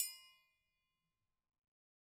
Triangle6-Hit_v2_rr2_Sum.wav